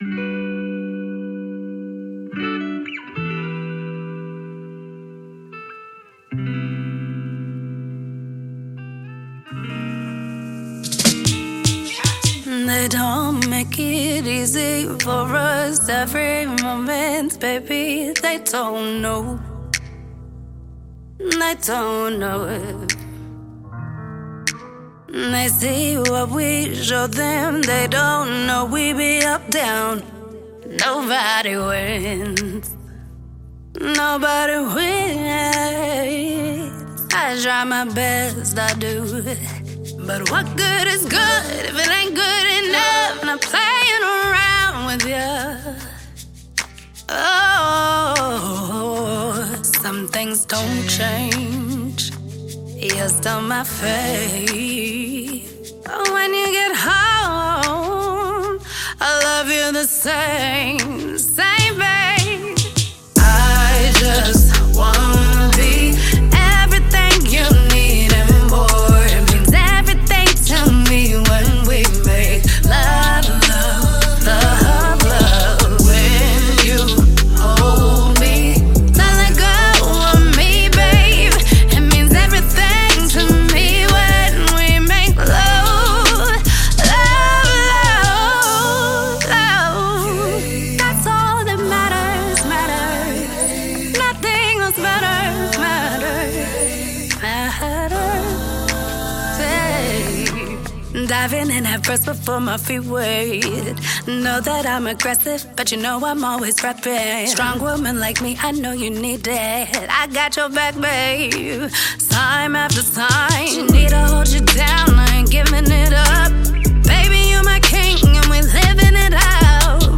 Indie RnB